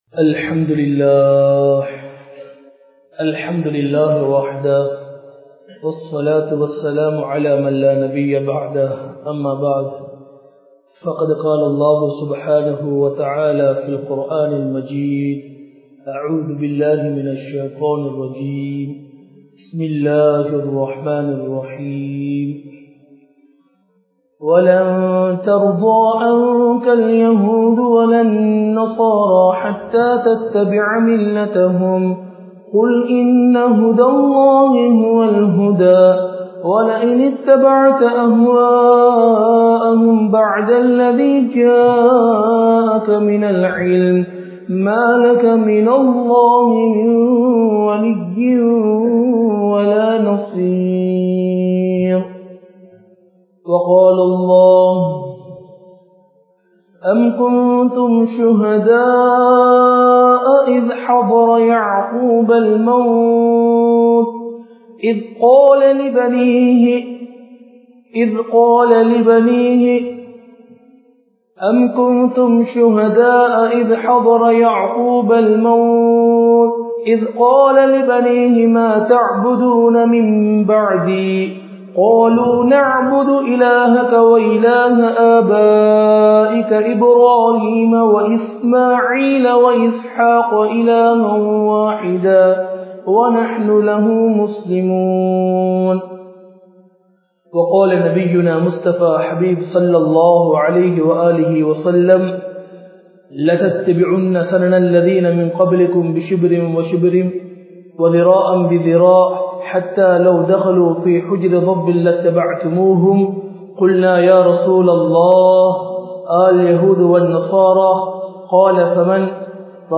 Naam Ean Sothikka Paduhintroam? (நாம் ஏன் சோதிக்கப்படுகின்றோம்?) | Audio Bayans | All Ceylon Muslim Youth Community | Addalaichenai